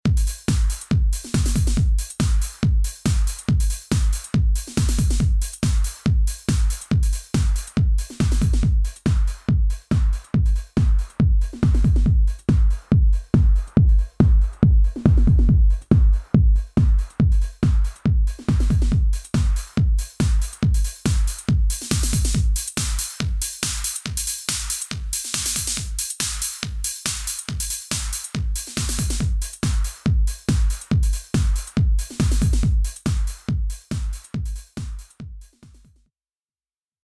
3）T4にEQをかけた音